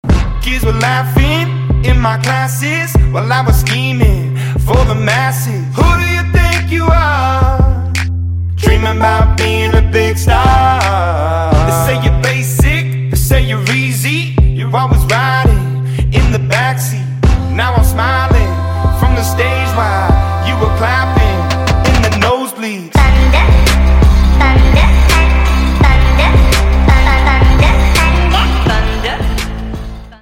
• Качество: 128, Stereo
мужской вокал
Хип-хоп
alternative